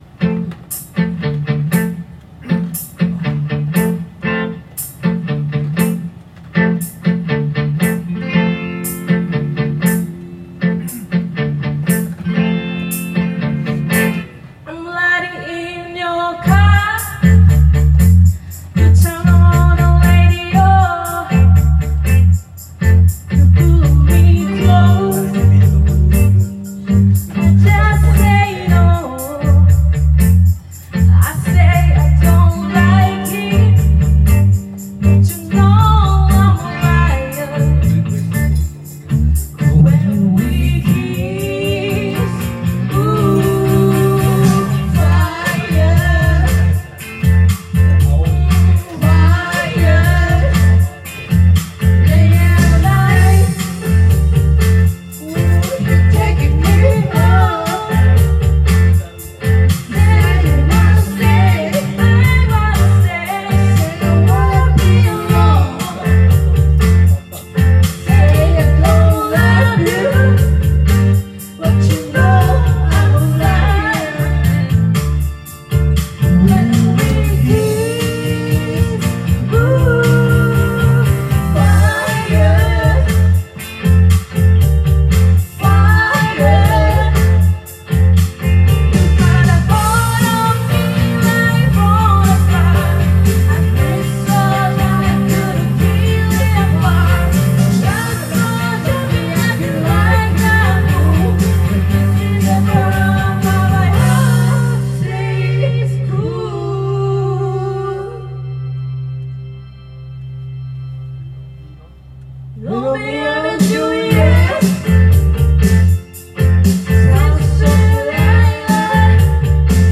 Duet & Chorus Night Vol. 21 TURN TABLE